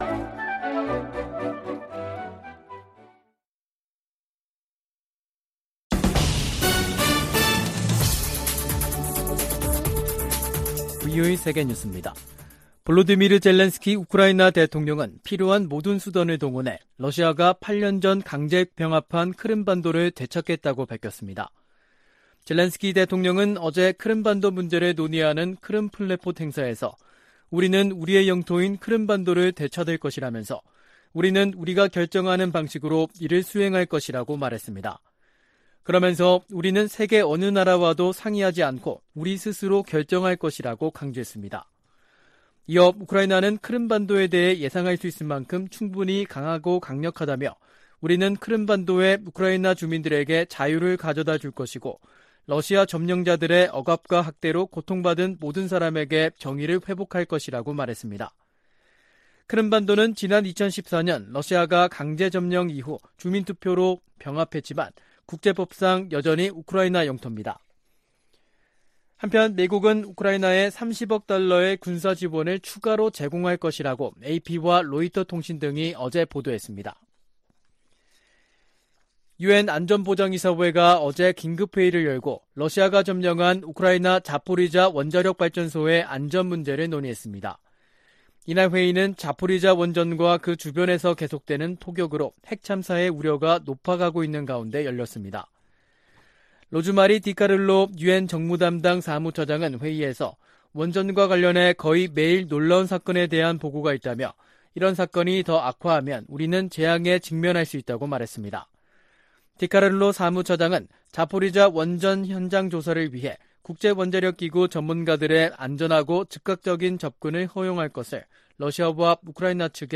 VOA 한국어 간판 뉴스 프로그램 '뉴스 투데이', 2022년 8월 24일 2부 방송입니다. 제10차 핵확산금지조약(NPT) 평가회의가 한반도의 완전한 비핵화를 지지하는 내용이 포함된 최종 선언문 초안을 마련했습니다. 에드워드 마키 미 상원의원은 아시아태평양 동맹과 파트너들이 북한의 핵 프로그램 등으로 실질적 위협에 직면하고 있다고 밝혔습니다. 미국 정부가 미국인의 북한 여행 금지조치를 또다시 연장했습니다.